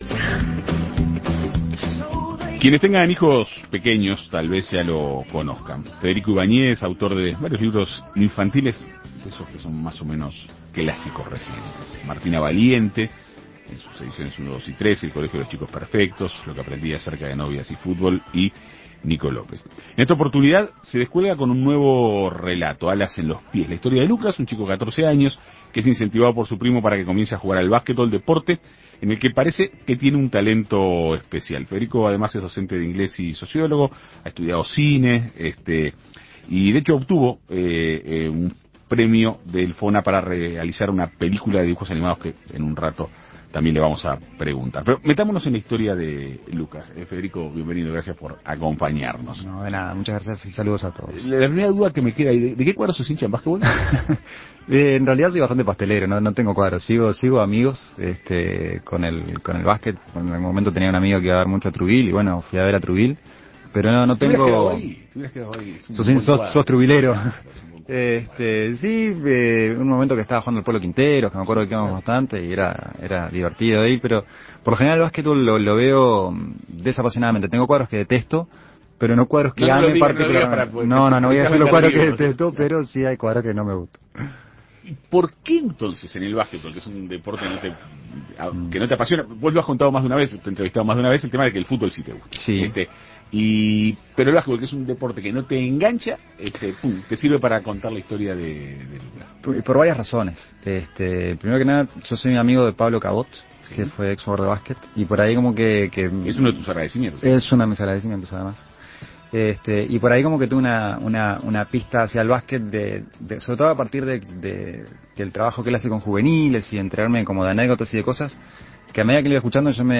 En Perspectiva Segunda Mañana dialogó con el autor.